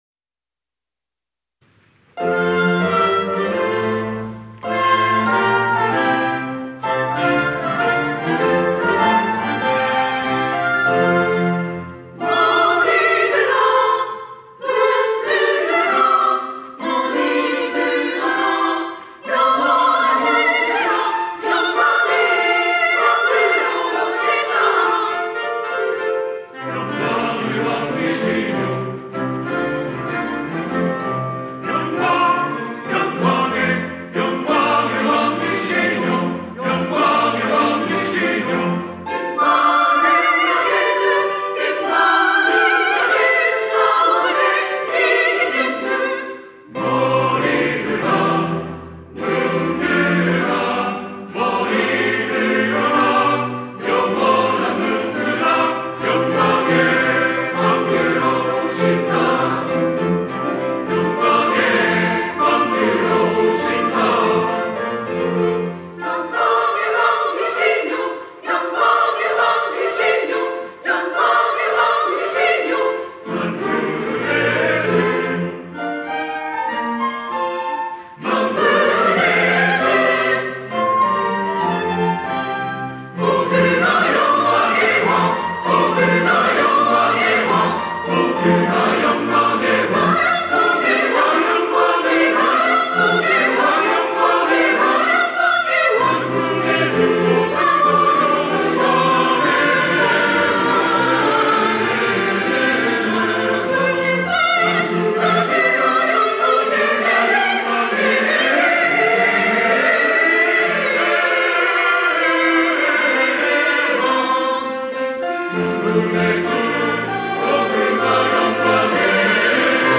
호산나(주일3부) - 머리들라 문들아
찬양대 호산나